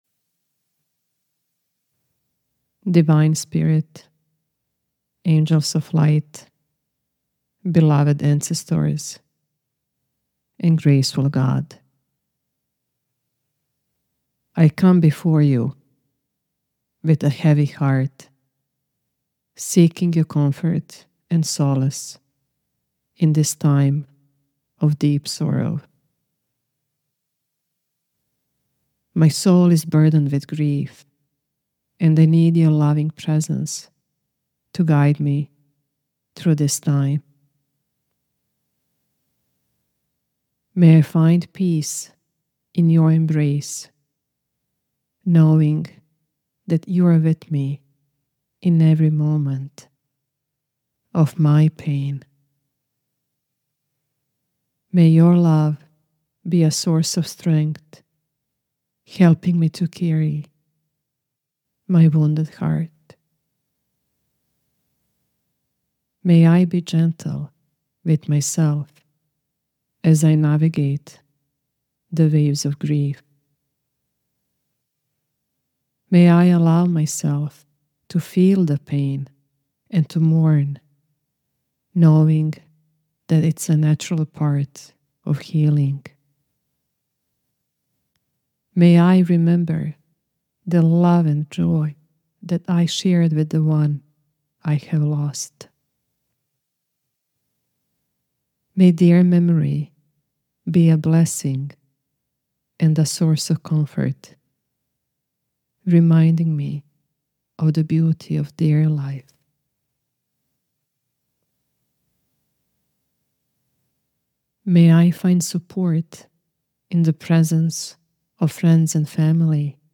• A gentle invocation of Divine Spirit, ancestors, and light
• A guided prayer that can hold the death of someone you love